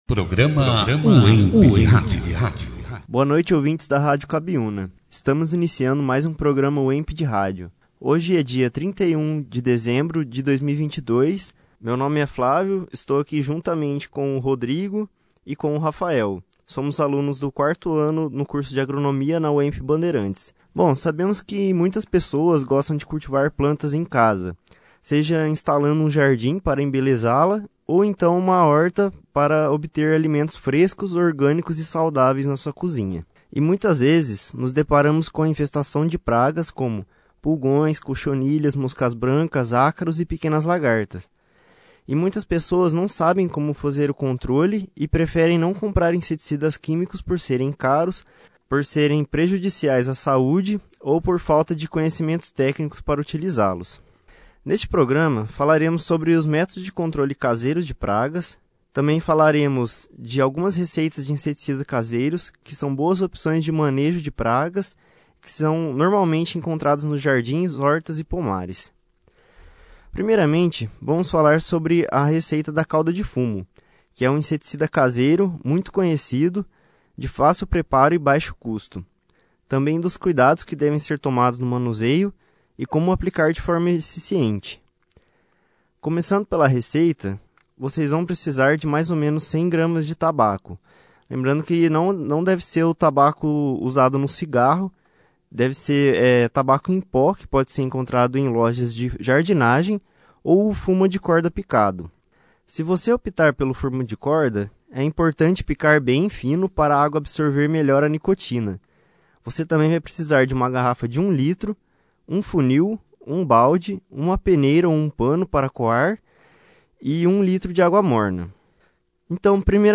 Produzido e apresentado pelos alunos, Acadêmicos do 4º ano do curso de Agronomia